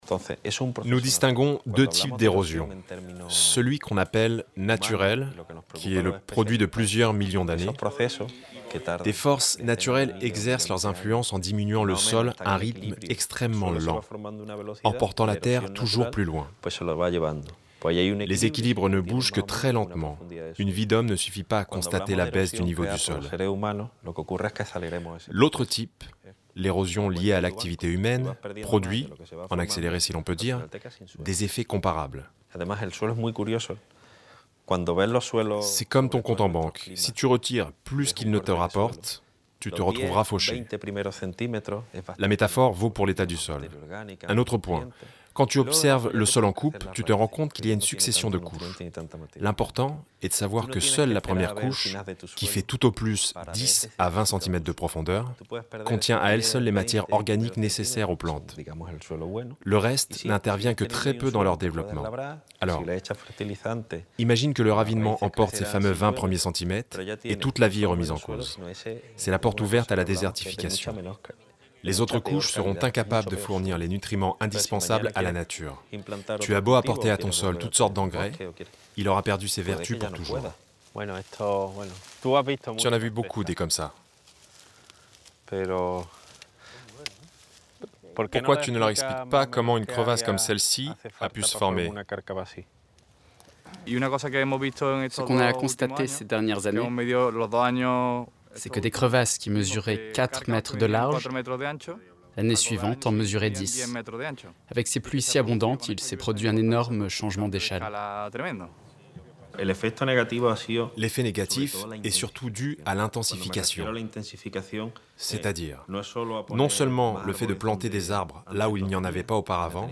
Voix off documentaire